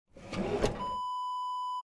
Kitchen Drawer Open Wav Sound Effect #6
Description: The sound of a kitchen drawer pulled open
Properties: 48.000 kHz 16-bit Stereo
A beep sound is embedded in the audio preview file but it is not present in the high resolution downloadable wav file.
Keywords: kitchen, slide, sliding, roll, out, rollout, drawer, shelf, push, pull, open
drawer-kitchen-open-preview-6.mp3